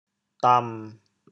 潮州 loh8 gu3 dam7 潮阳 loh8 gu3 dam7 潮州 0 1 2 潮阳 0 1 2